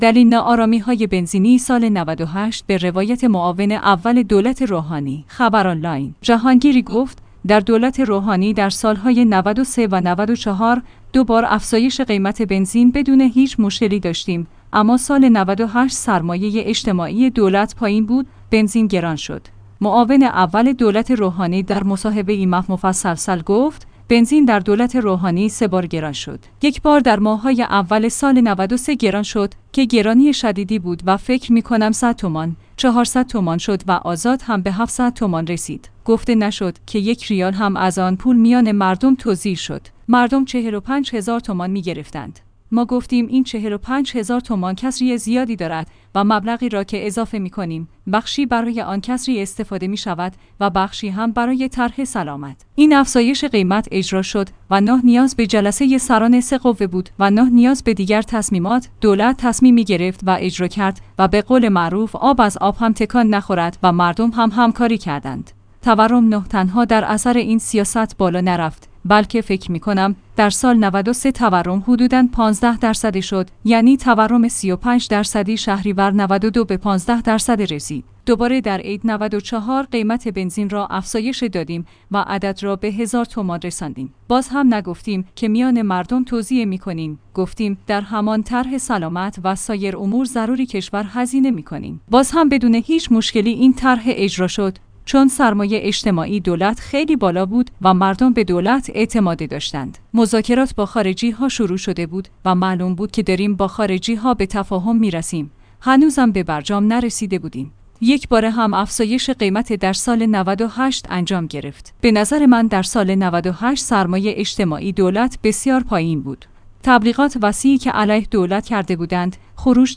معاون اول دولت روحانی در مصاحبه ای مفصل گفت: بنزین در دولت روحانی ۳ بار گران شد.